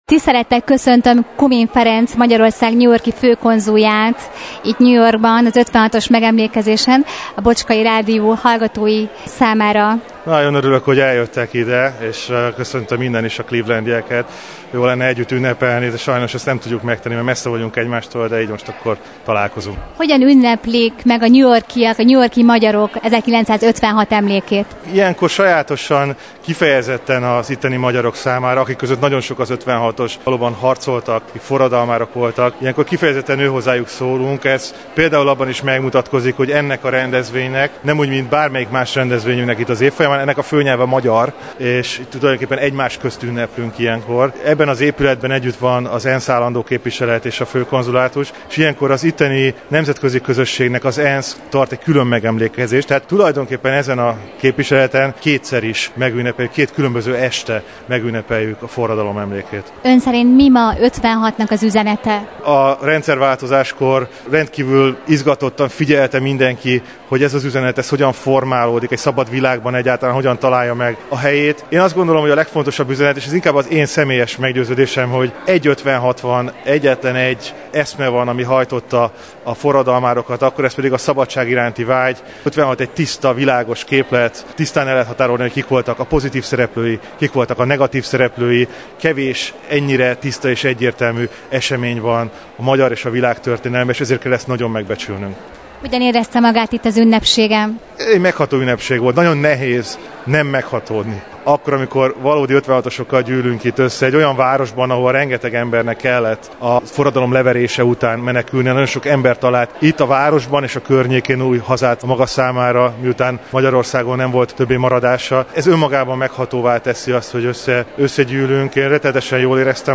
Interjú Kumin Ferenccel Magyarország New York-i főkonzuljával az 56-os megemlékezés kapcsán – Bocskai Rádió
Magyarország Főkonzulátusán megtartott 1956-os forradalom és szabadságharc megemlékezést követően, Kumin Ferenc Magyarország New York-i főkonzulja adott rádiónknak interjút a megemlékezéssel kapcsolatban.